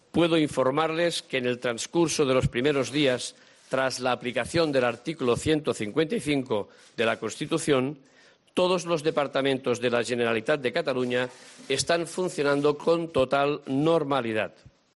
En una comparecencia en Barcelona para dar cuenta de los acuerdos del Consejo de Ministros relacionados con la convocatoria de las elecciones autonómicas del próximo 21 de diciembre, Millo ha agradecido, en nombre del Gobierno central, la "profesionalidad" y "colaboración" de todos los funcionarios de la Generalitat.